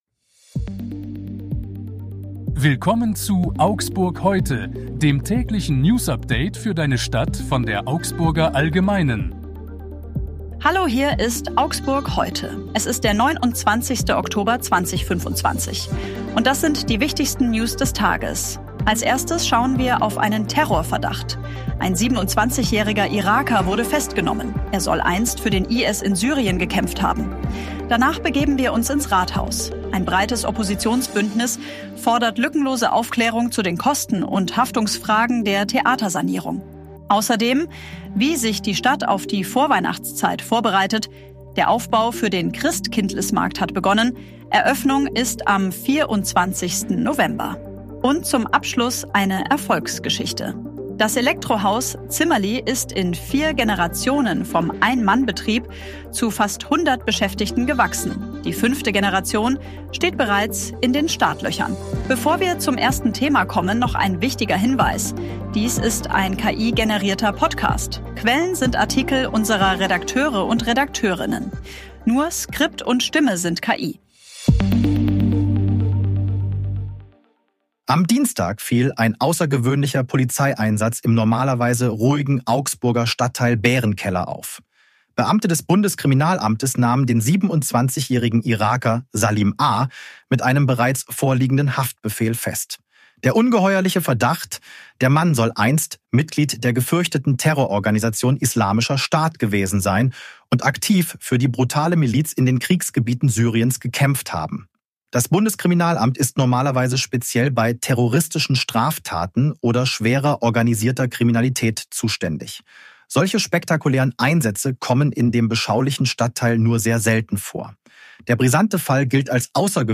Hier ist wieder das tägliche Newsupdate für deine Stadt.
erfolgreich Dies ist ein KI-generierter Podcast.